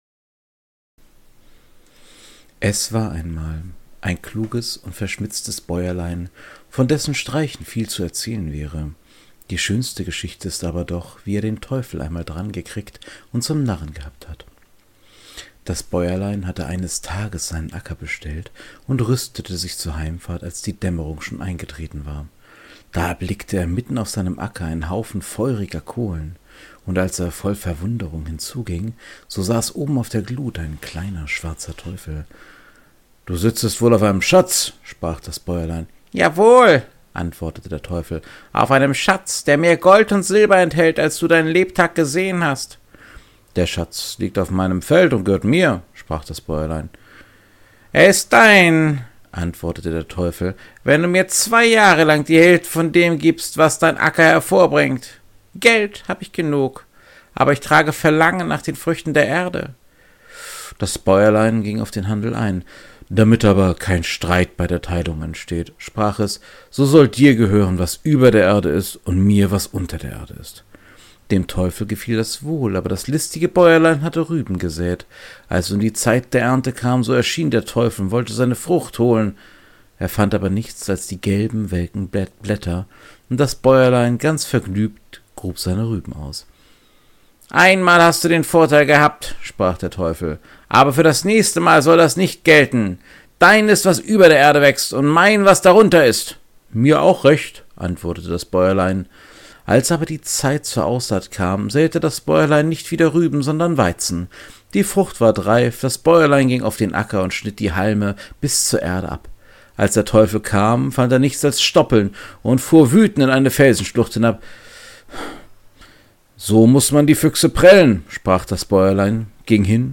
In diesem kleinen Podcast Projekt lese ich Märchen vor.